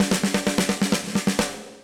AM_MiliSnareB_130-02.wav